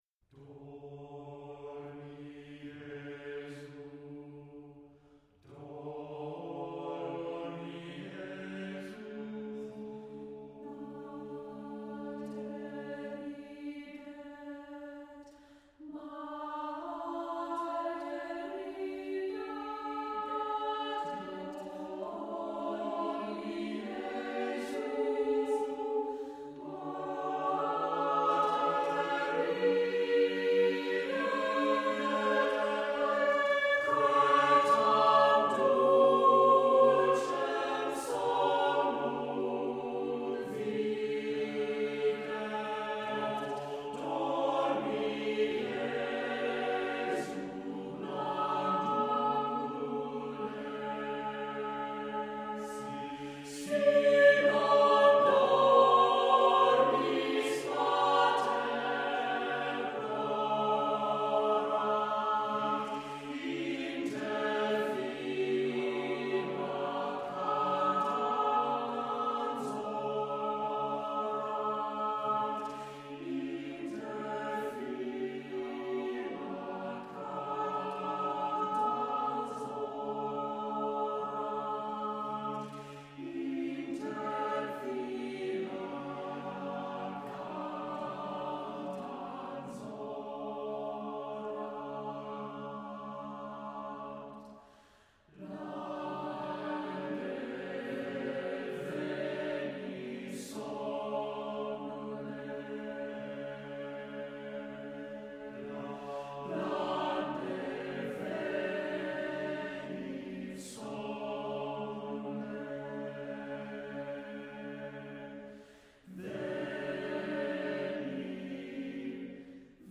Accompaniment:      Reduction
Music Category:      Choral